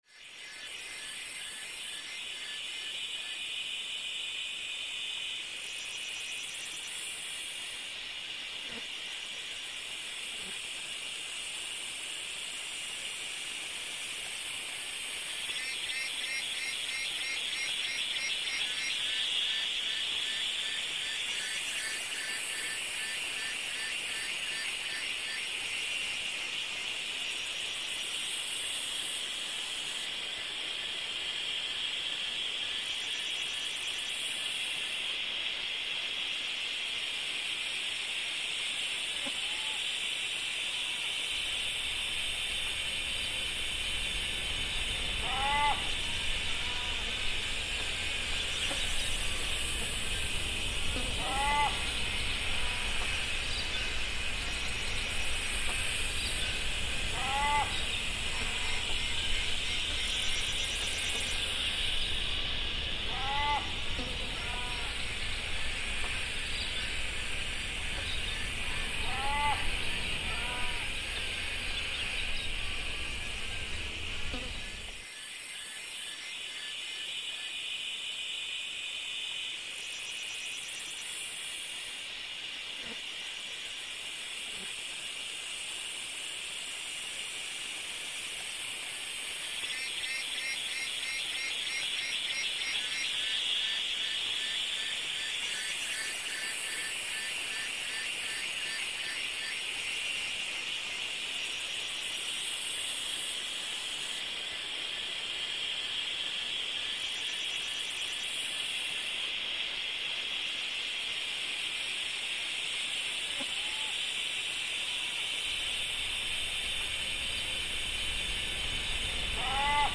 2030_cicadacrow_fadeinout.mp3